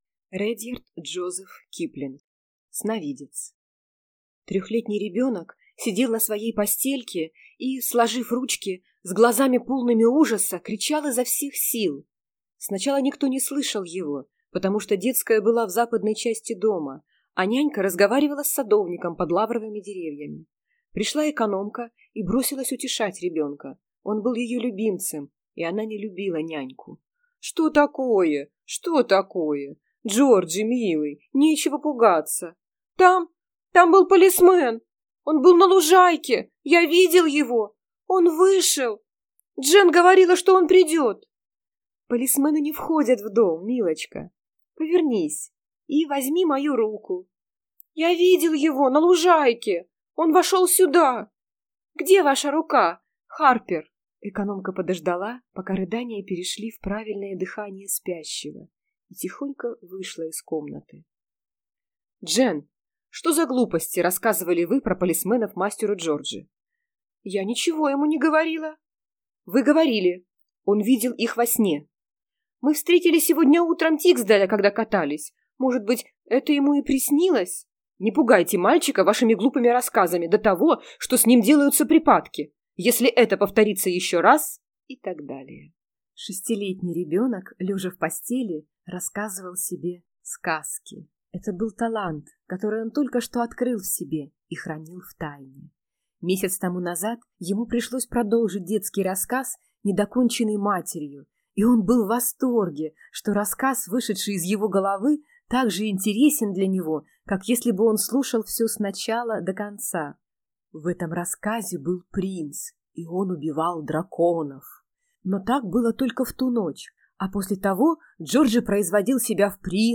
Аудиокнига Сновидец | Библиотека аудиокниг